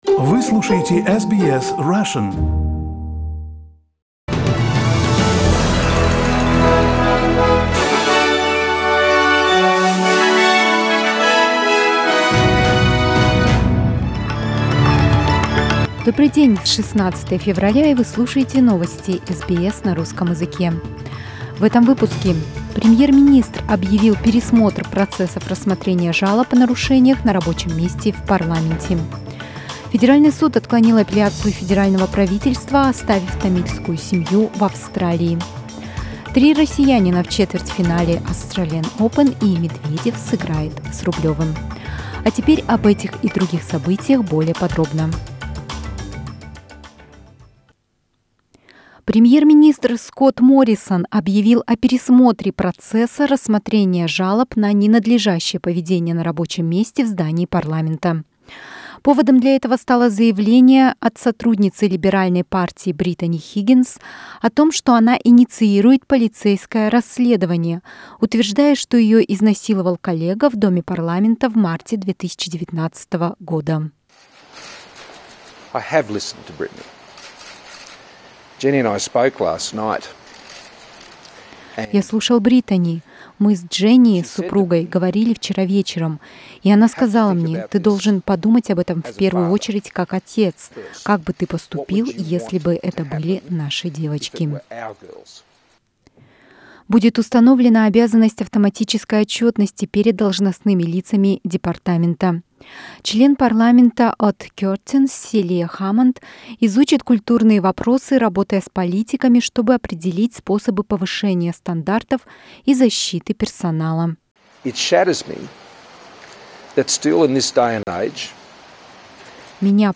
Новостной выпуск за 16 февраля